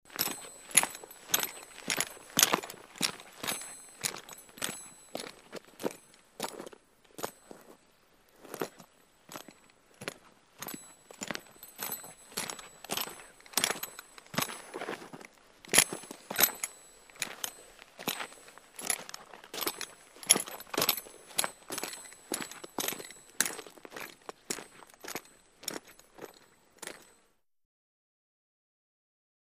SpursPaceMediumOnD BB013801
Spurs Pace Medium On Dirt; Boots On Dirts With Metal Movement Walking Steady With Spur Jingles On Impact Walk, Stop, Walk, Turn And Away. Close-up.